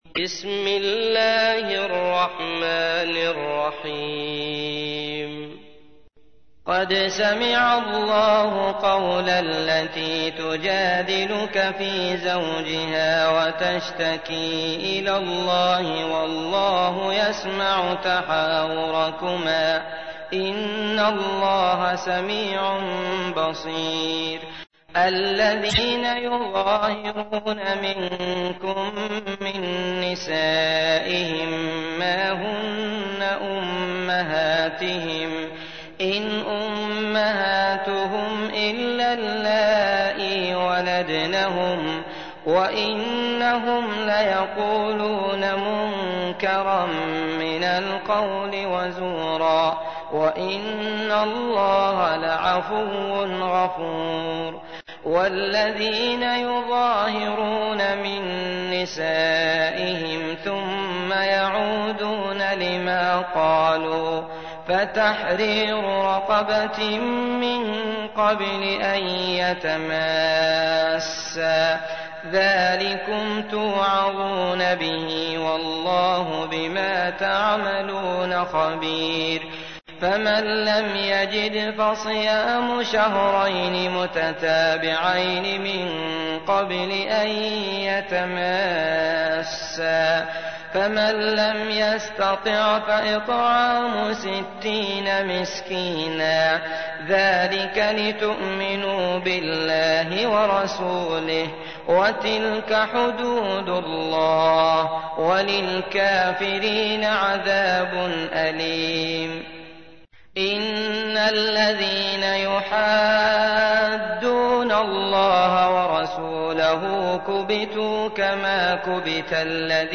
تحميل : 58. سورة المجادلة / القارئ عبد الله المطرود / القرآن الكريم / موقع يا حسين